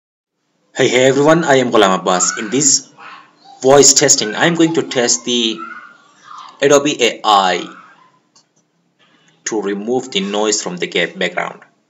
In my case, I have recorded a voice using the Windows Sound recorder, the format is MP3. You can listen to it without editing and enhancing it.